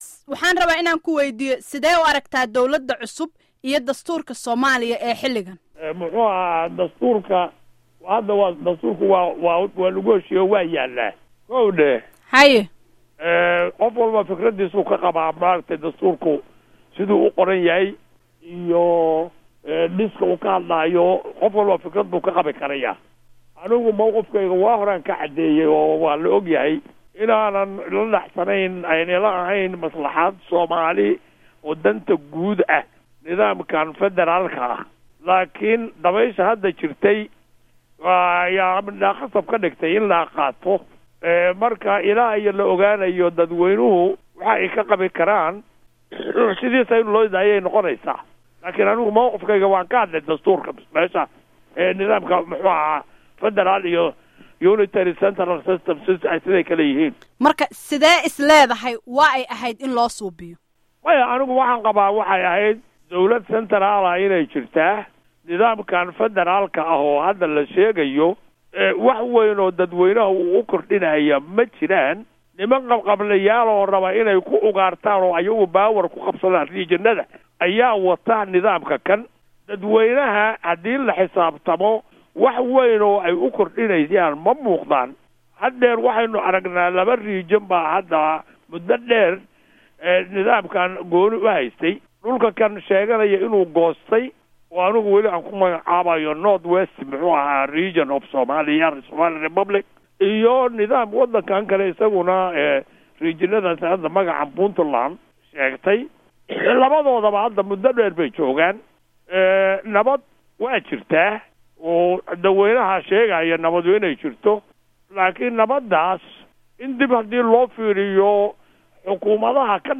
Dhageyso Wareysiga Cabdirisaaq Xaaji Xuseen